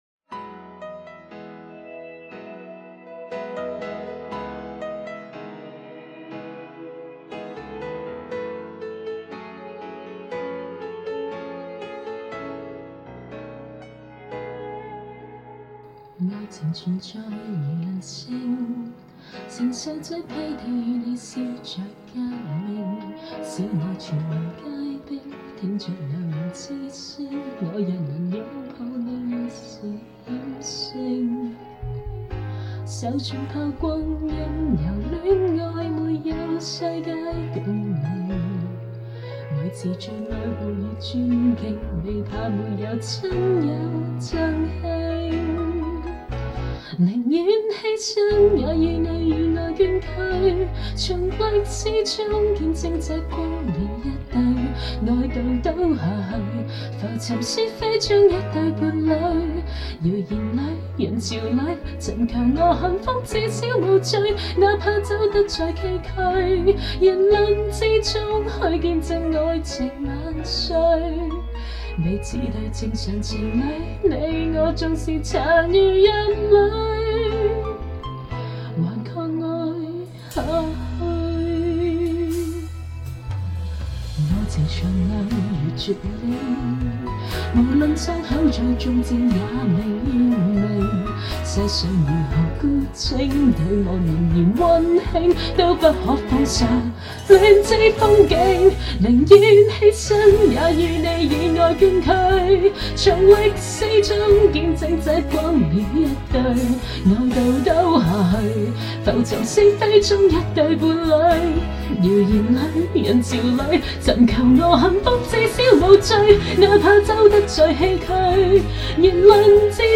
哈哈, 謝謝﹗ 有機會一定再來唱唱歌 :slight_smile: 這次是感冒了才有時間在家裡錄.